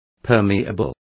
Complete with pronunciation of the translation of permeable.